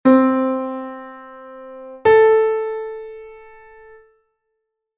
do_la_sexta.mp3